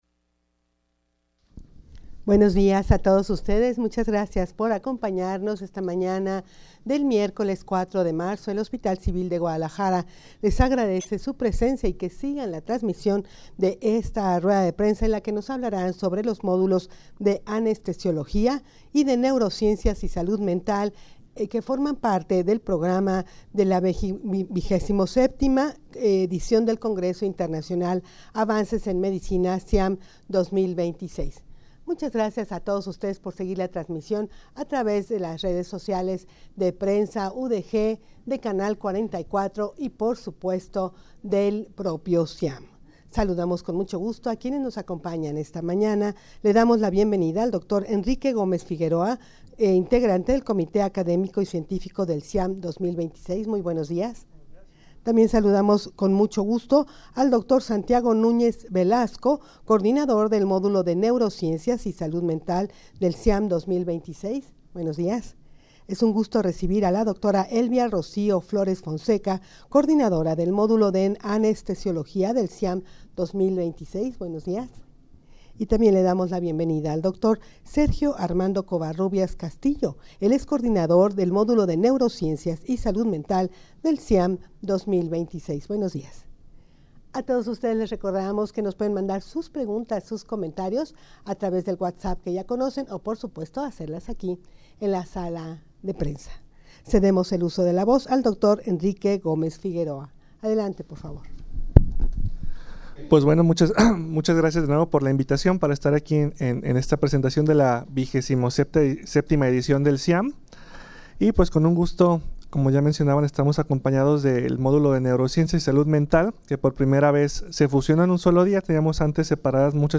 rueda-de-prensa-sobre-los-modulos-de-anestesiologia.-de-neurociencias-y-salud-mental-xxvii-ciam-2026.mp3